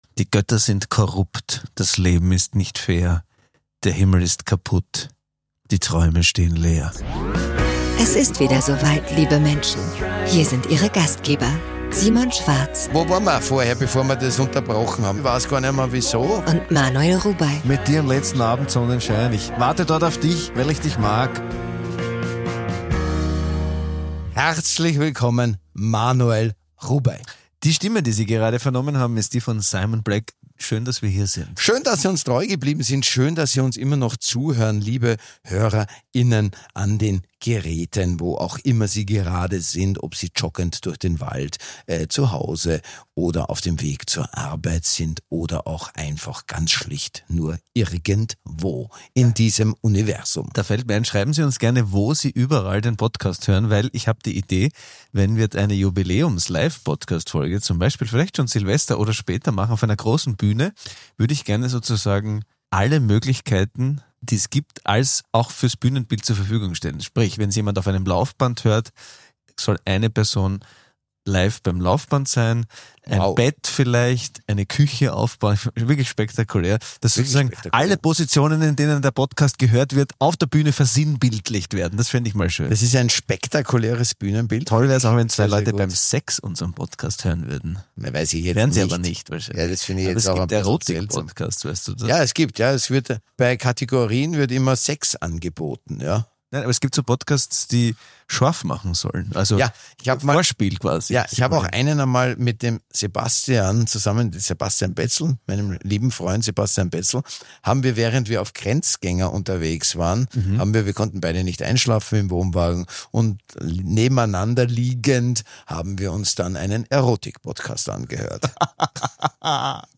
Die Fröhlichkeit ist zurück, wenngleich man sich mit diversen Phantasiesprachen auf unsicheres Terrain begibt.